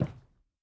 added stepping sounds
Parquet_Floor_Mono_04.wav